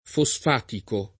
[ fo S f # tiko ]